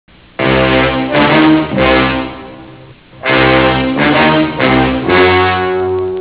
TV series theme